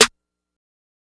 young metro snare.wav